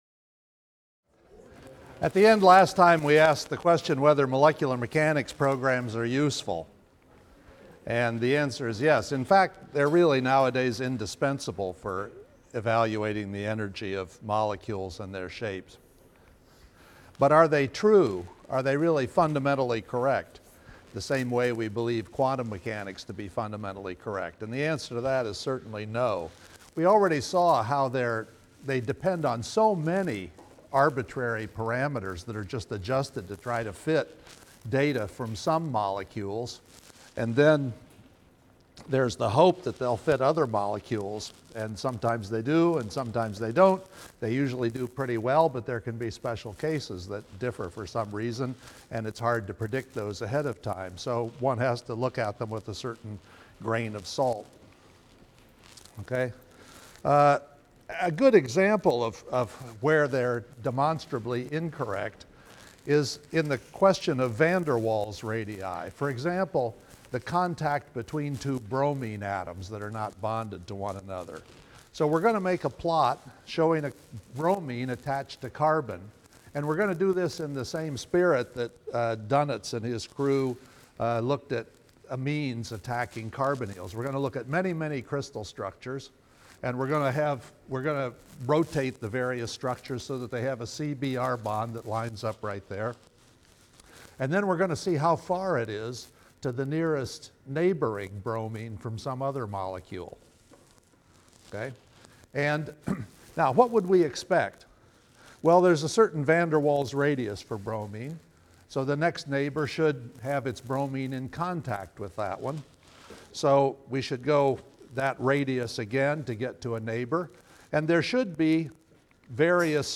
CHEM 125a - Lecture 35 - Understanding Molecular Structure and Energy through Standard Bonds | Open Yale Courses